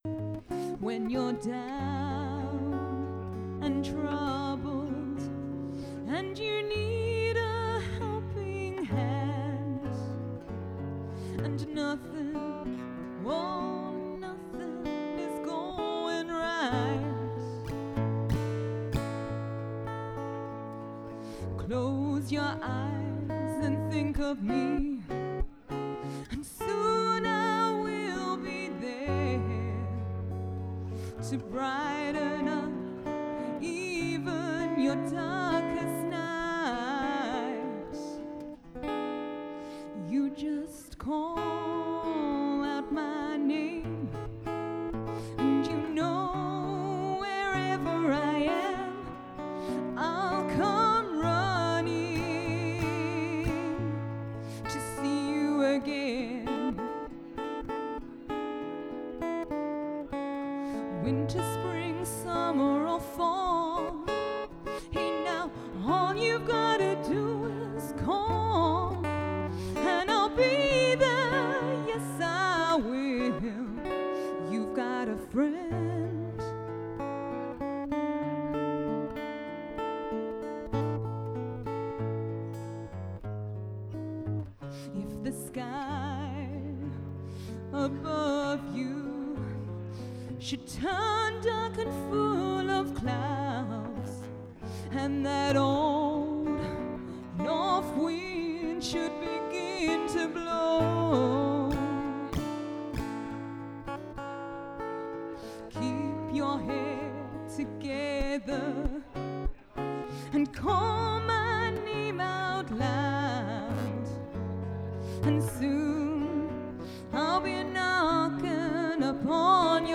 Live recording with Guitarist